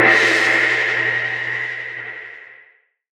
Index of /musicradar/impact-samples/Processed Hits
Processed Hits 03.wav